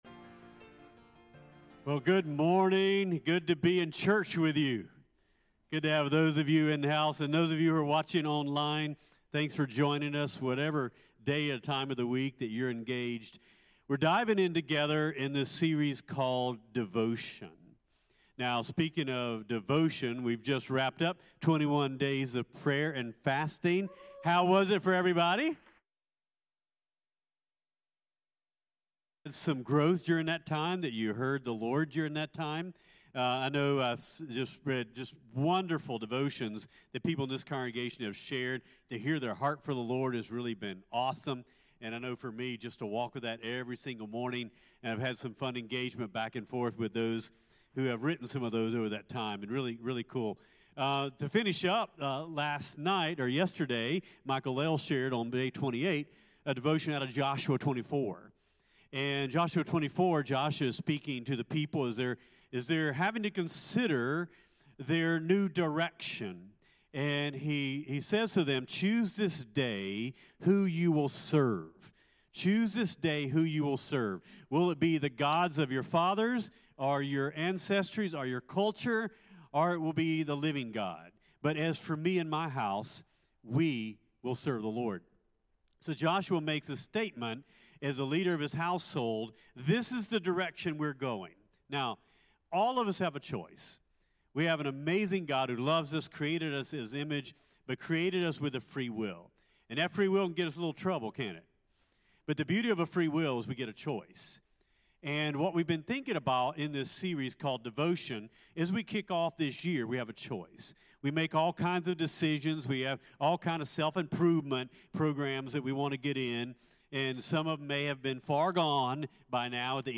CTK-Clipped-Sermonmp4.mp3